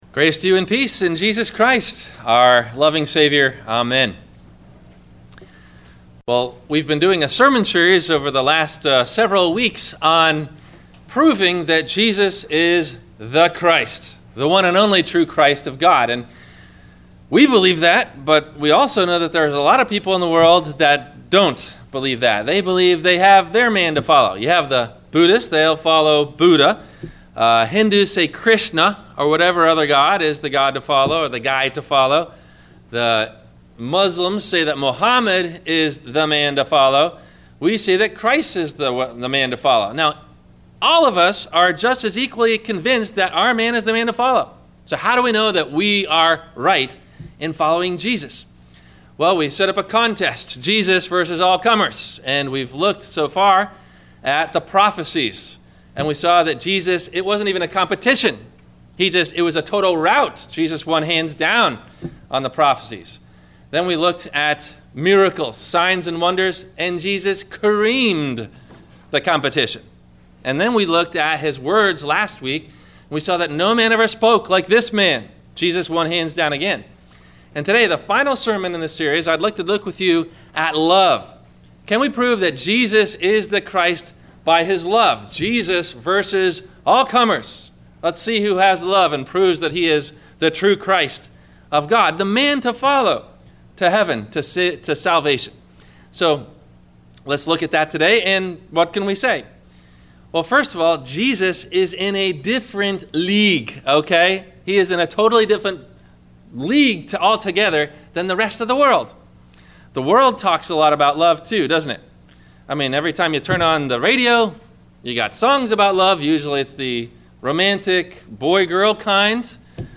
Questions to think about before you hear the Sermon: